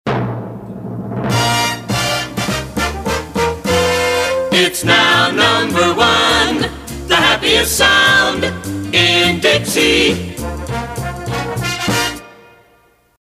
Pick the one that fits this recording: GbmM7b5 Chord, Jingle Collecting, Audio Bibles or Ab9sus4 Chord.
Jingle Collecting